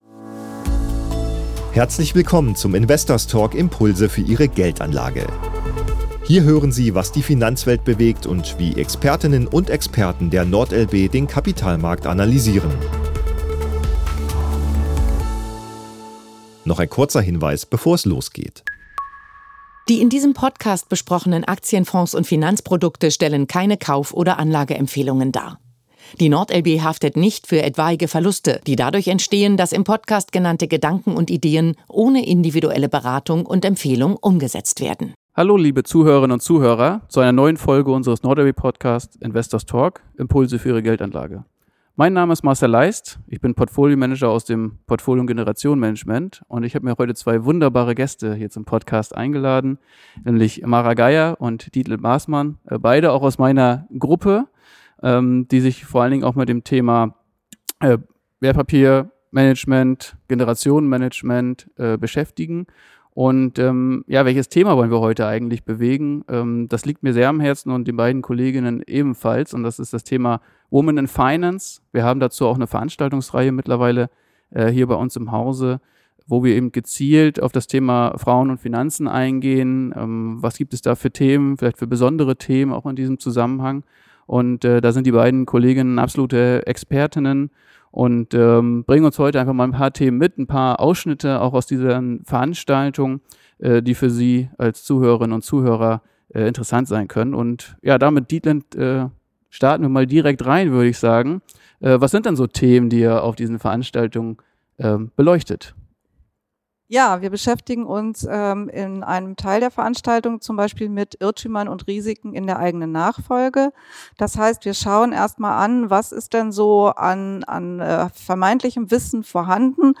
Gemeinsam beleuchten die drei Gesprächspartner zudem gesellschaftliche und rechtliche Aspekte wie Generalvollmachten, Patientenverfügungen und internationale Güterstände.